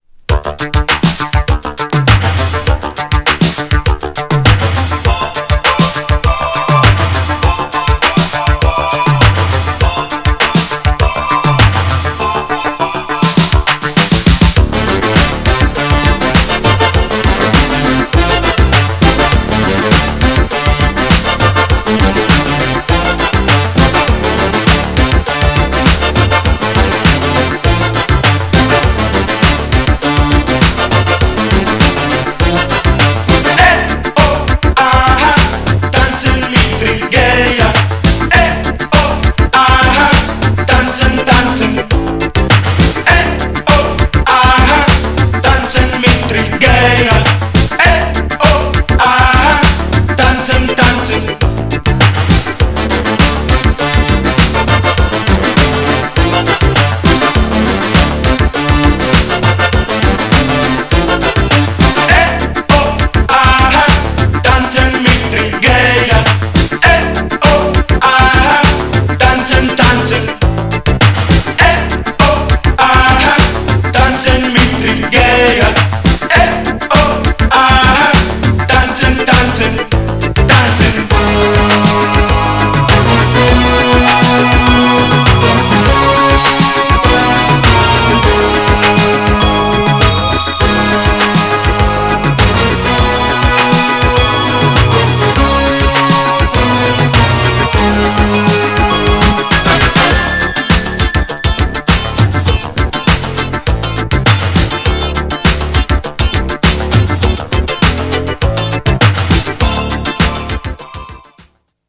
ラテンノリのキャッチーなメロディラインでヒットしたイタロDISCO!!
GENRE Dance Classic
BPM 126〜130BPM